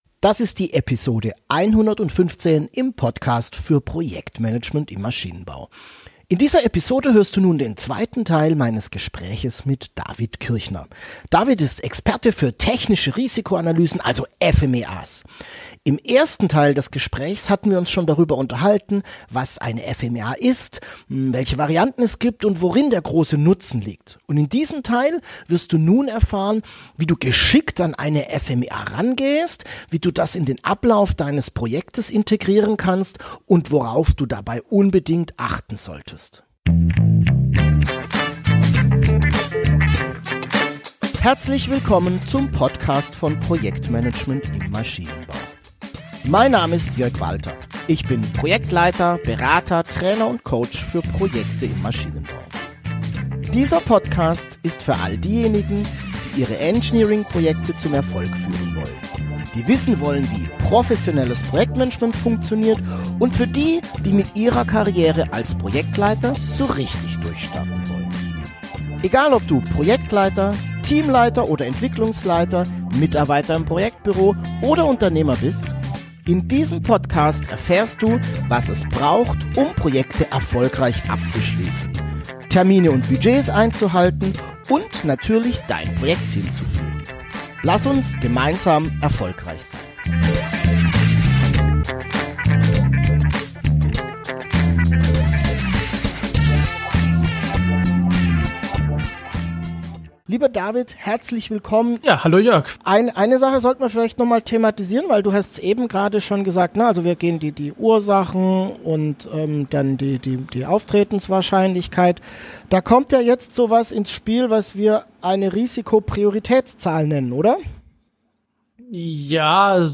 PMMB115: FMEA auf den Punkt gebracht - Expertengespräch